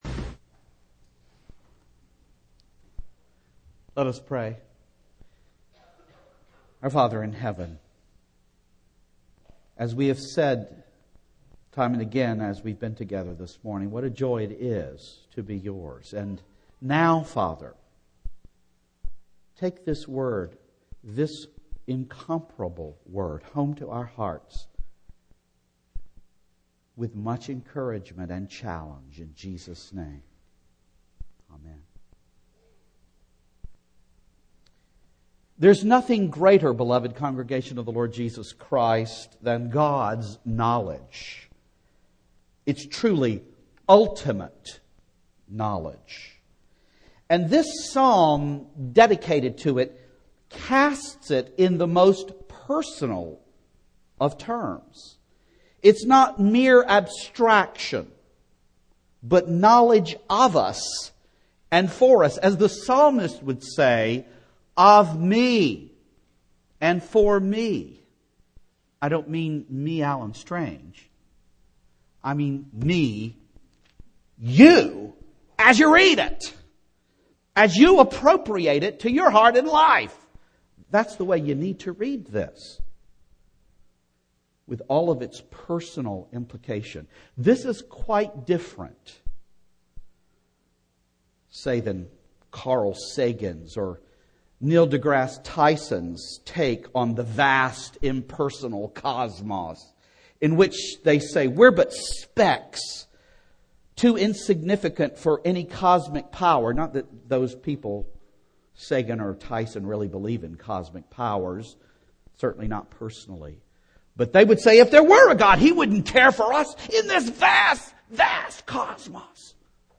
Single Sermons
Service Type: Morning